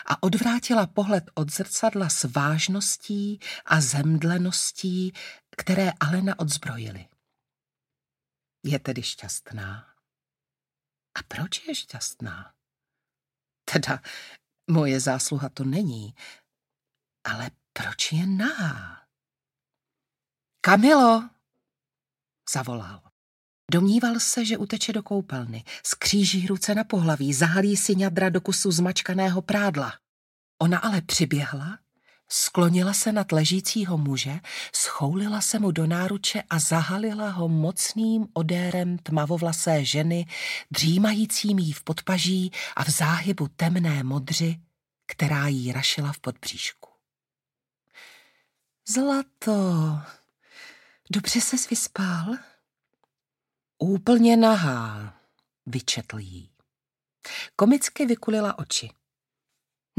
Audiobook
Read: Martina Preissová